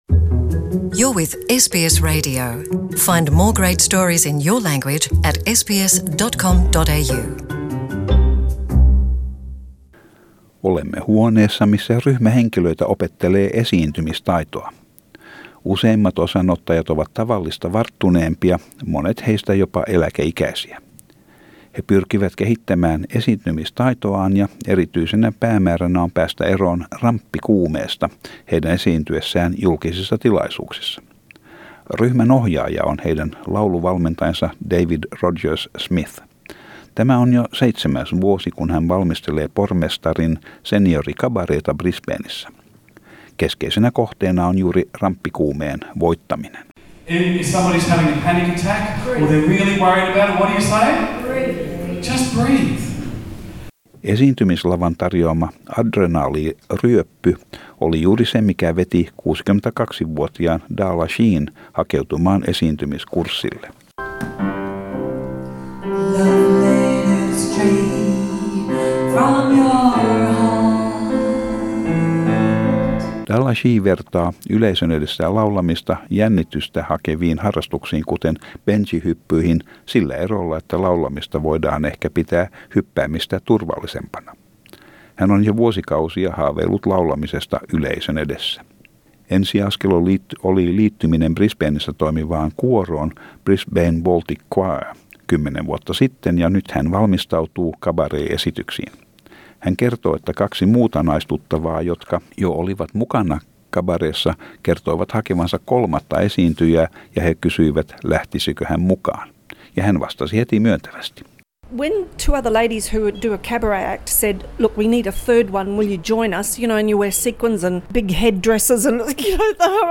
Tämän jutun alku tapahtuu huoneessa missä ryhmä henkilöitä opettelee esiintymistaitoa.